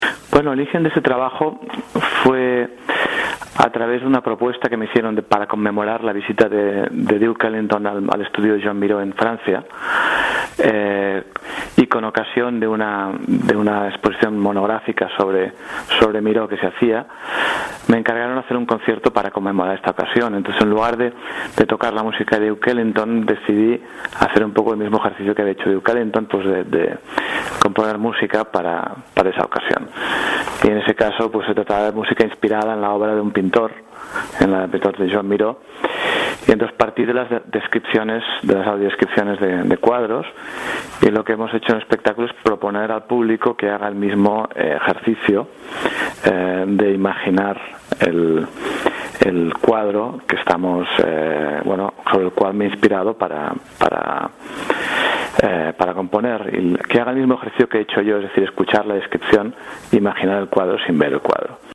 uno de los locales obligados para los amantes del jazz.